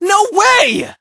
hotshot_hurt_07.ogg